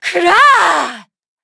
Xerah-Vox_Attack1_Madness.wav